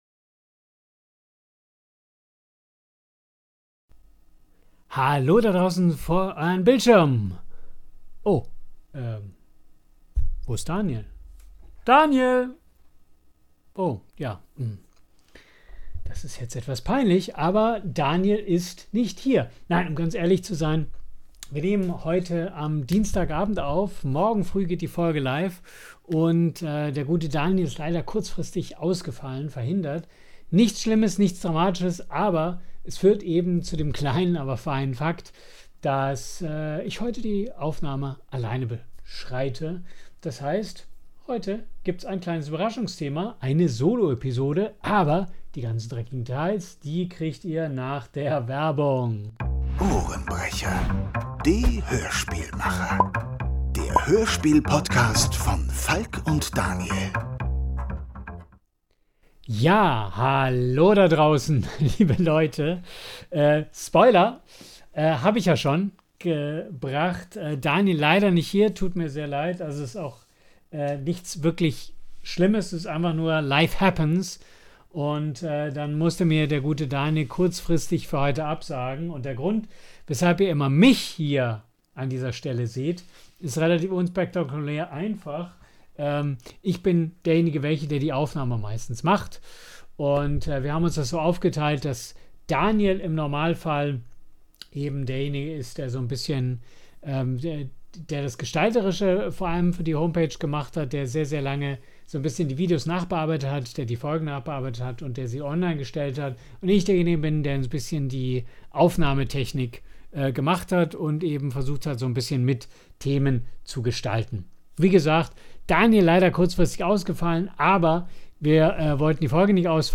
Mit spannenden Soundbeispielen direkt aus der Produktion!